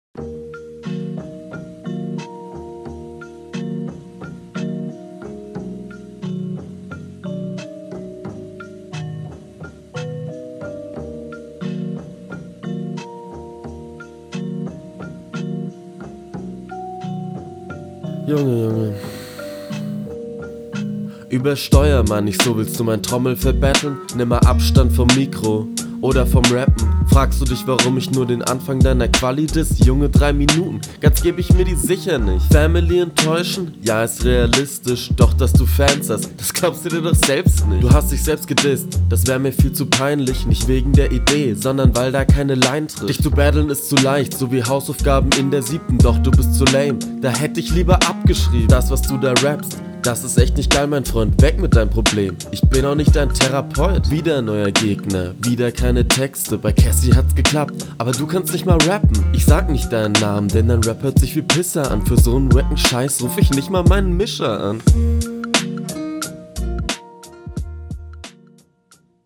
Rap ist durchweg im Takt, Stimmeinsatz klingt noch recht redelastig, Betonungen sind ganz gut auf …
Flow: Der Flow an sich hört sich gut an.